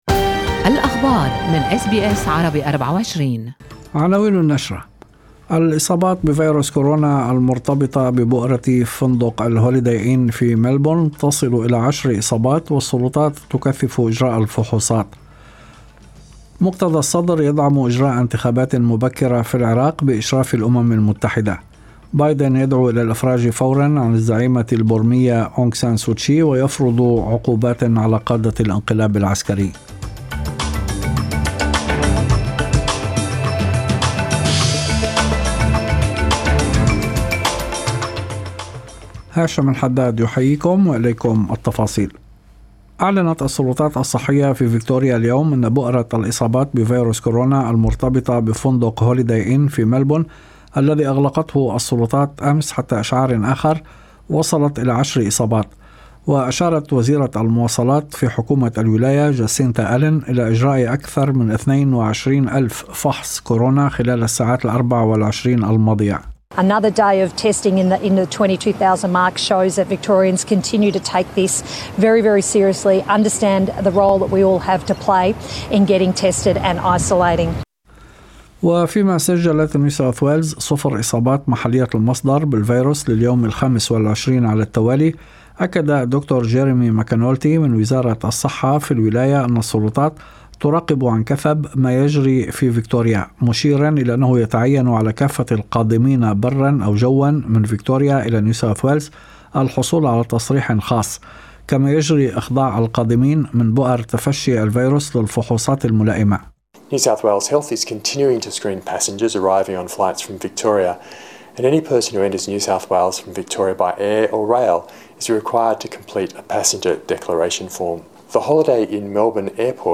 نشرة أخبار المساء11/2/2021